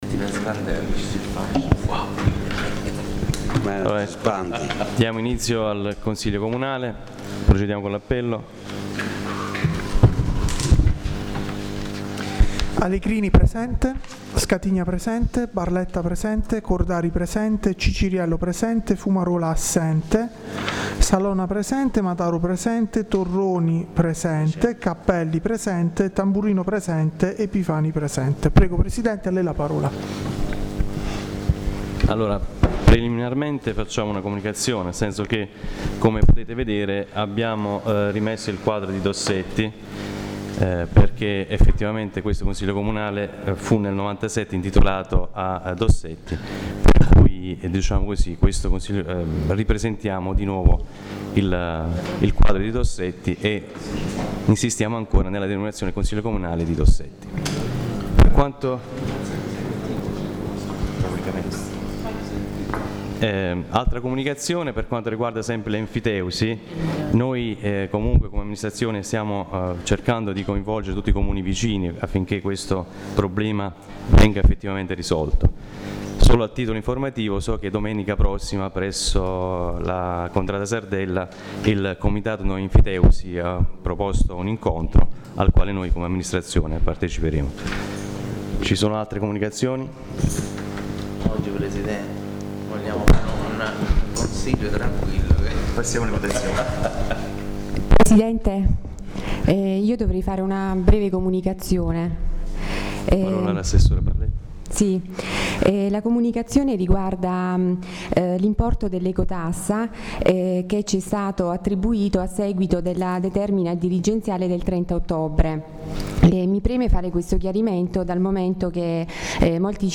La registrazione audio del Consiglio Comunale di San Michele Salentino del 10/11/2017.